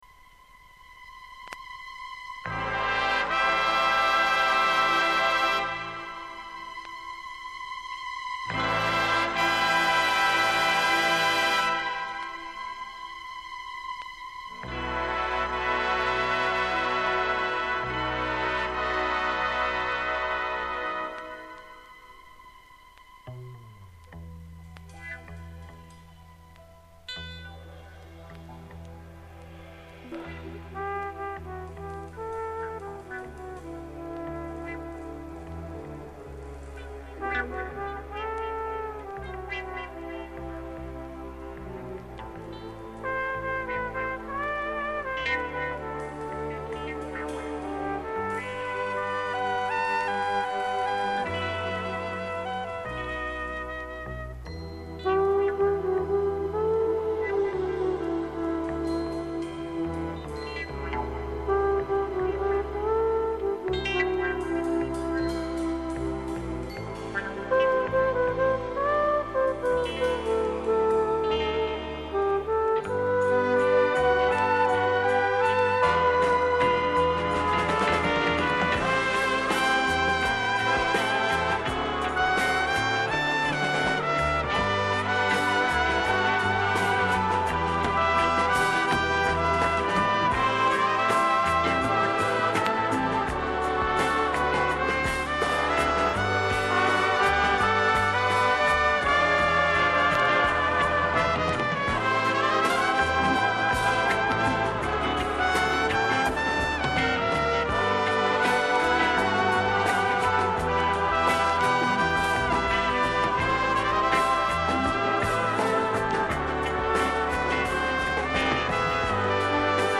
Музыкант-трубач из Чехословакии